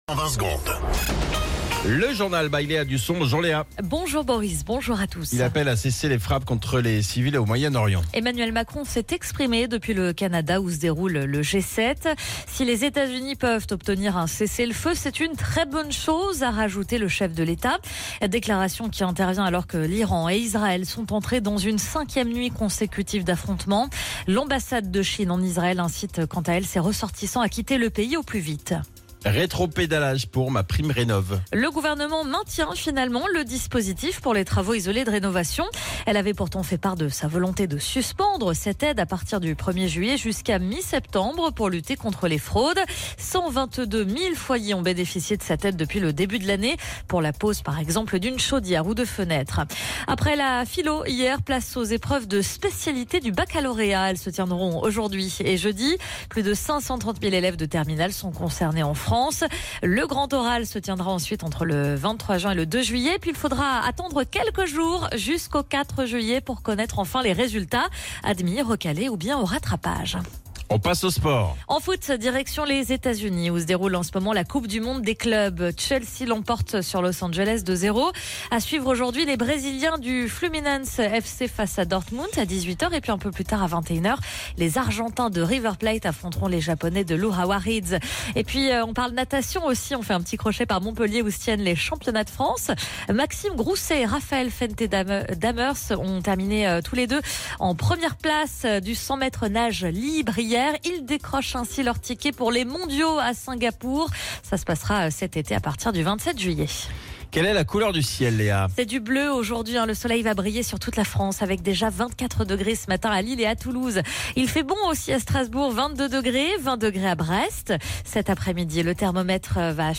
Flash Info National 17 Juin 2025 Du 17/06/2025 à 07h10 .